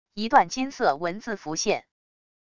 一段金色文字浮现wav音频